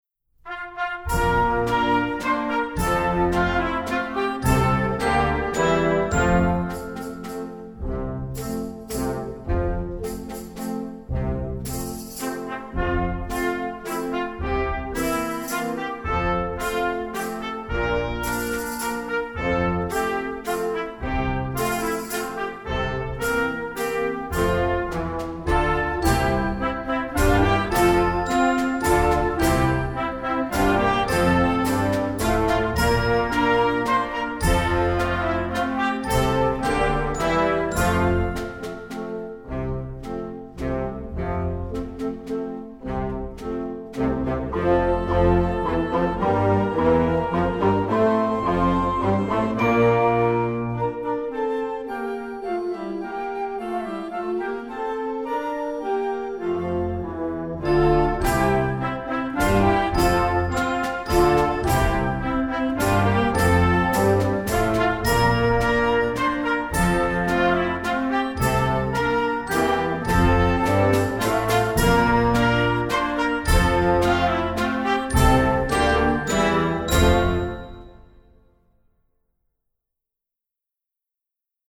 latin, multicultural